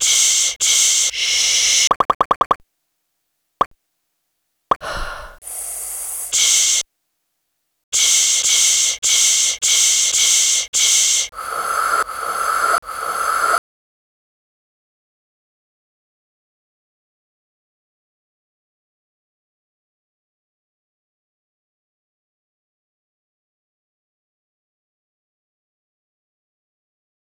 sehr schöne lautmalerische miniatur. das gut proportionierte und fein rhythmisierte stück würde auch auch ohne seinen titel U7 funktionieren, der ein geräuschrätsel auflöst, das als solches gar nicht nötig wäre.